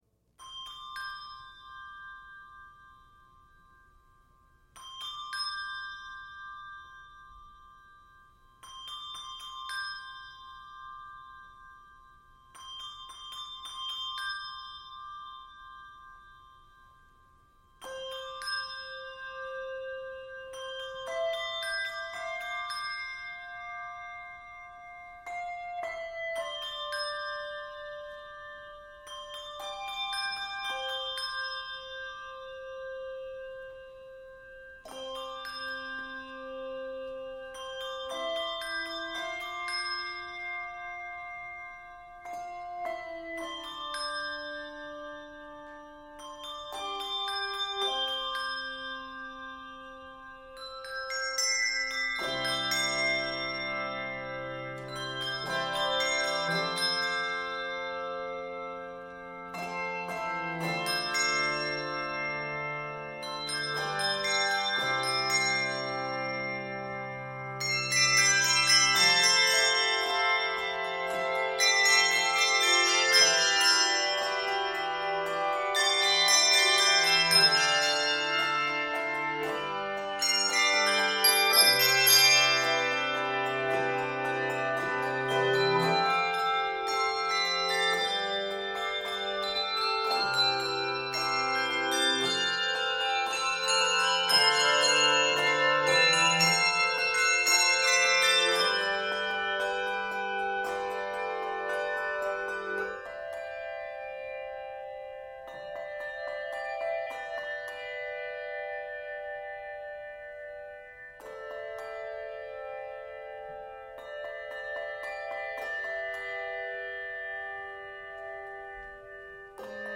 N/A Octaves: 3-7 Level